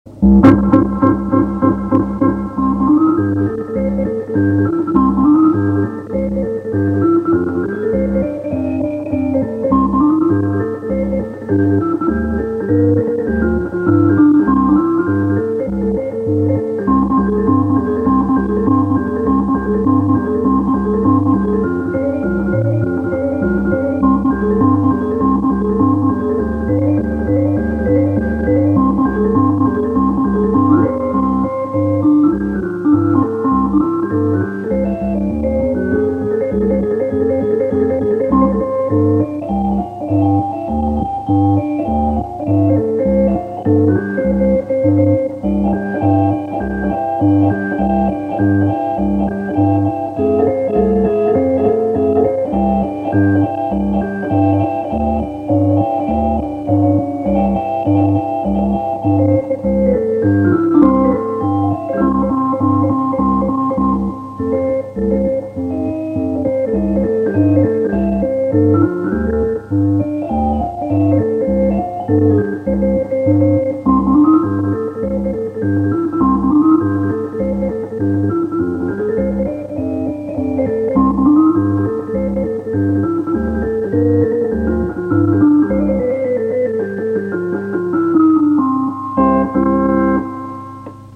Orgue électronique
mais les sonorités étaient un peu trop artificielles.